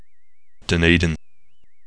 Ääntäminen
Ääntäminen NZ Haettu sana löytyi näillä lähdekielillä: englanti Käännöksiä ei löytynyt valitulle kohdekielelle.